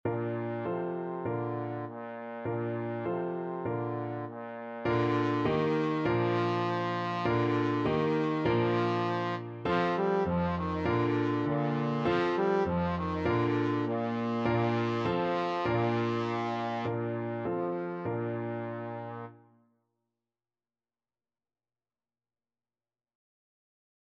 Trombone
Bb major (Sounding Pitch) (View more Bb major Music for Trombone )
4/4 (View more 4/4 Music)
Bb3-G4